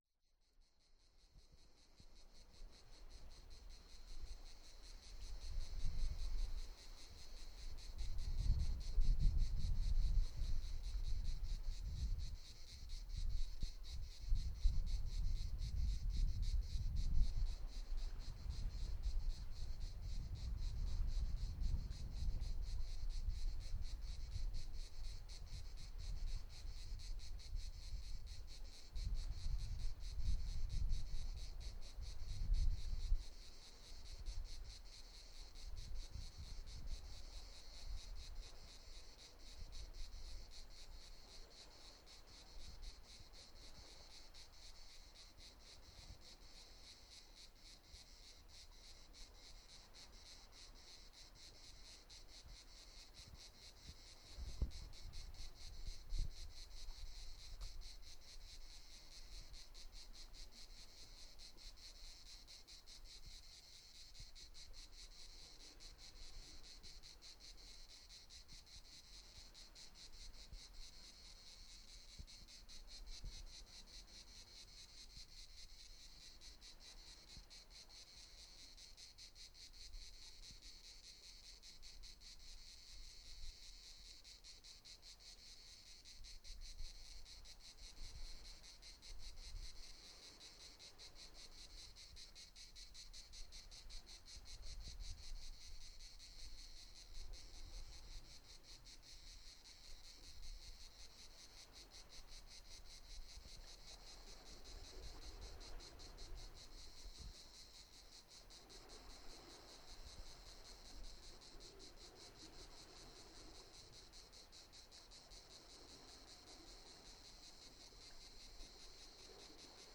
J'utilise un matos d'amateur mais suffisamment sérieux pour obtenir d'excellents résultats : enregistreur MD digital Sony MZ-R70, micro stéréo Sony ECM-MS907, casque DJ Sony MDR-V 500.
Les Cigales (Calanques de Cassis - France)
Passez un long moment avec les cigales de la calanque de Port Pin, à Cassis (Var).
Il est 10h30, par une belle matinée d'août. Il fait déjà chaud et les cigales viennent de commencer à chanter. Sortant des buissons et des pins parasols, leur "cri-cri" au rythme changeant accompagne le clapotis des vagues dans les rochers en contrebas. Vers la fin de cette promenade sonore, vous pouvez entendre le bruit de mes pas avançant sous l'ombre des arbres, foulant les cailloux et le tapis d'aiguilles de pin.
cigales1.mp3